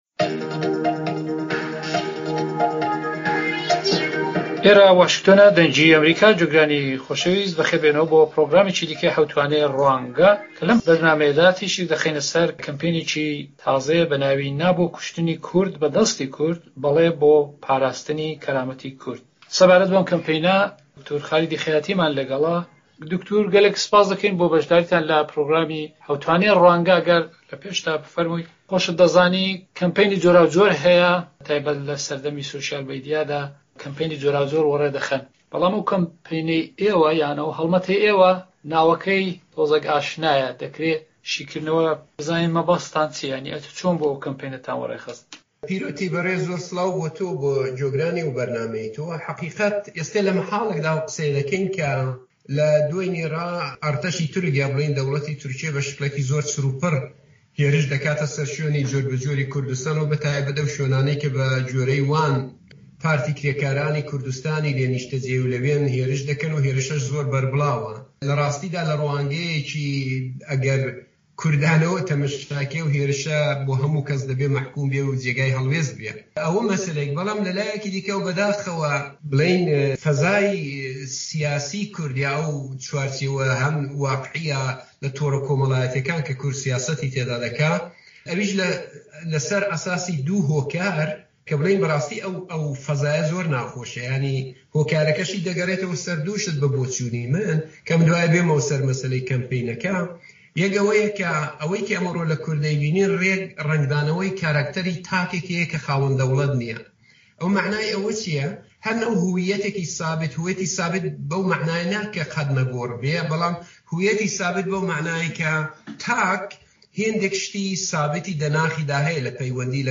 مێزگردی حەوتوانەی ڕوانگە